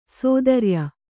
♪ sōdarya